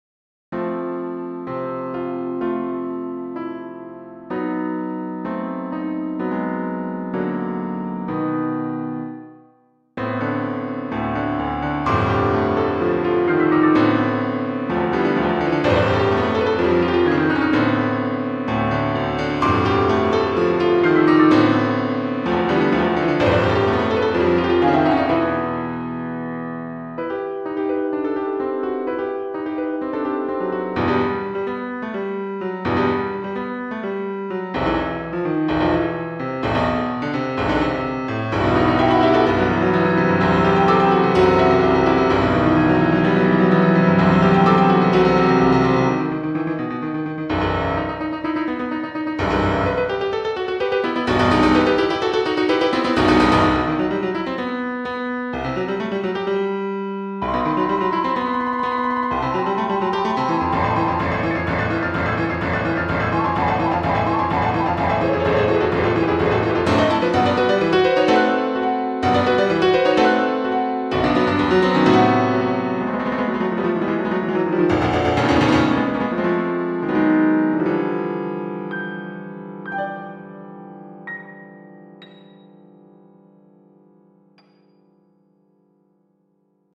Op.380 (WW3) - Piano Music, Solo Keyboard - Young Composers Music Forum